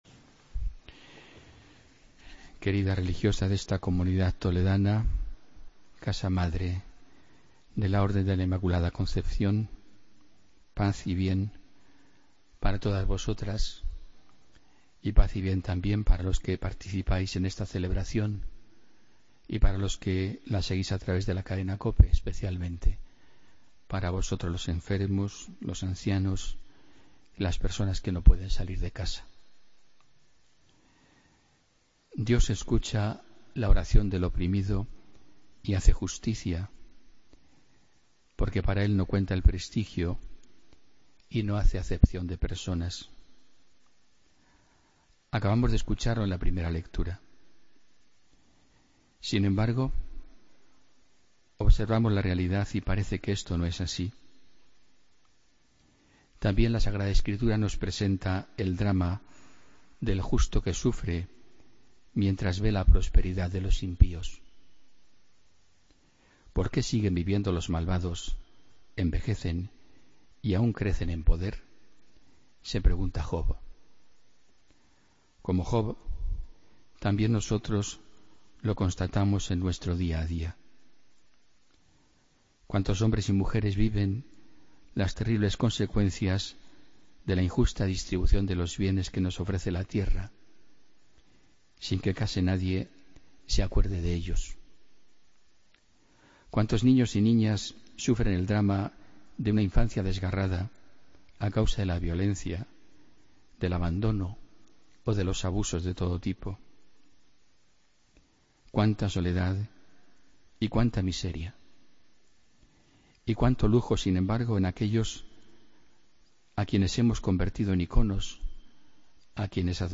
Homilía del domingo 23 de octubre de 2016